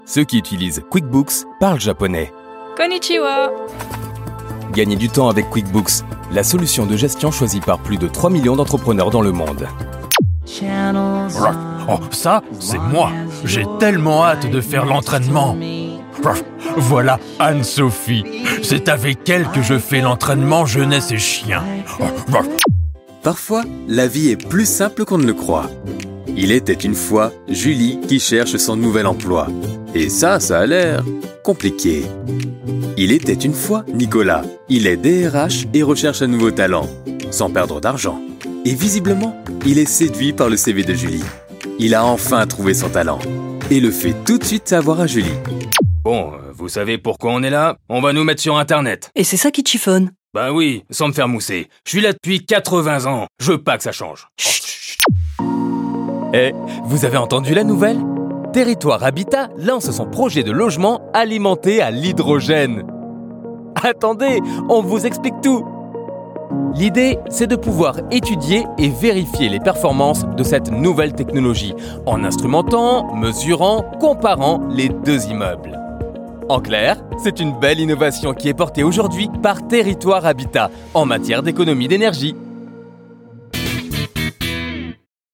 Freundlich, Vielseitig, Junge, Natürlich, Erwachsene
Unternehmensvideo